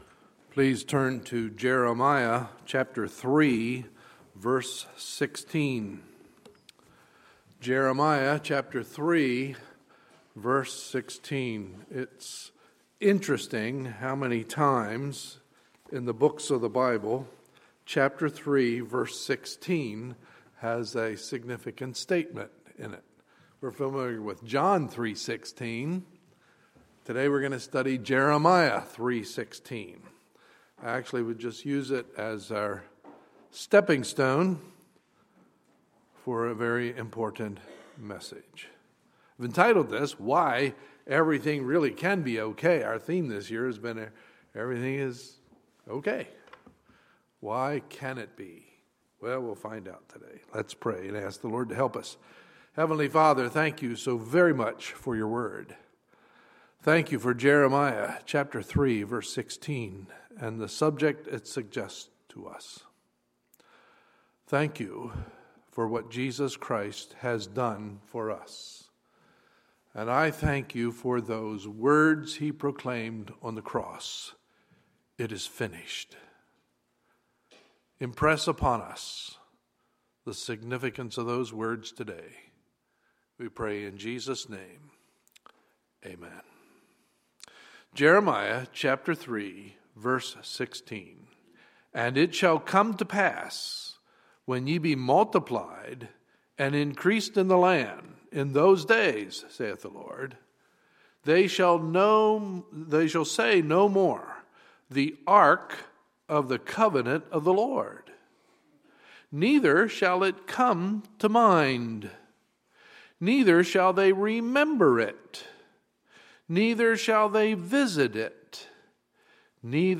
Sunday, December 6, 2015 – Sunday Morning Service
Sermons